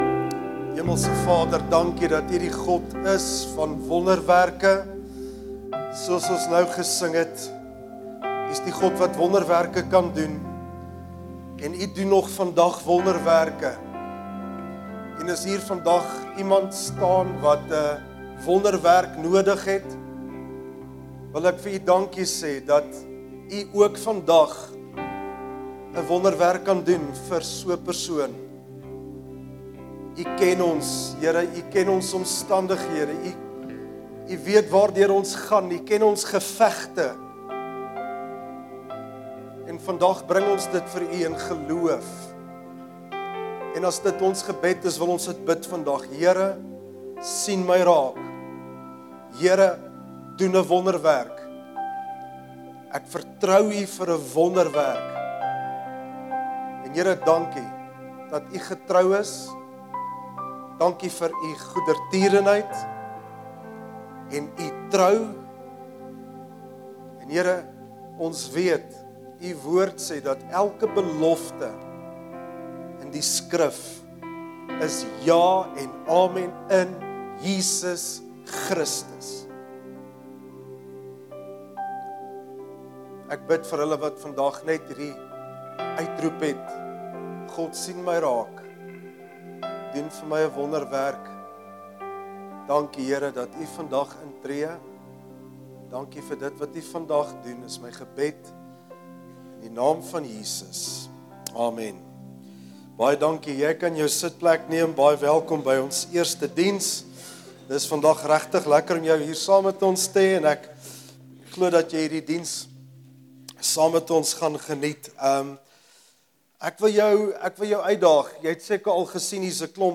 Sermons Archive - KruisWeg Gemeente